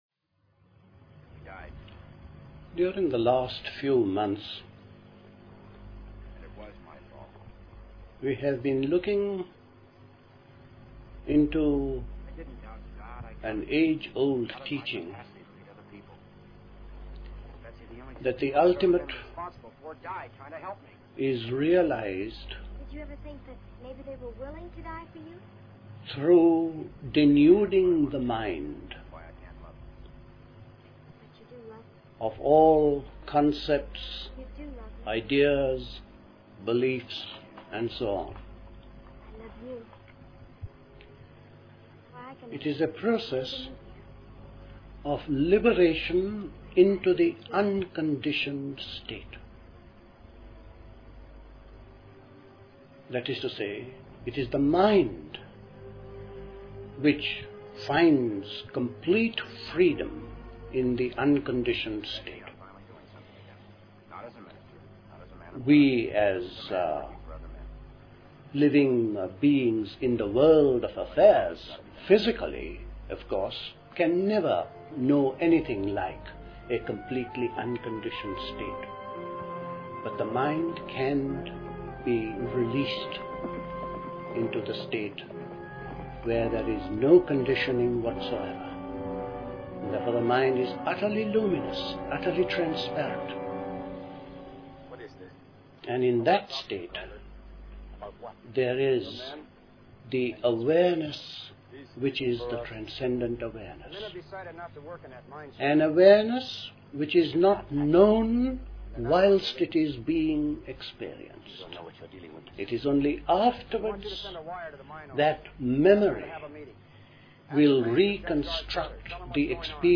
at Dilkusha, Forest Hill, London on 9th November 1969
15 th November 2018 What is playing in the background? Sounds like a movie!